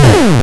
M1_Cannon_fast.wav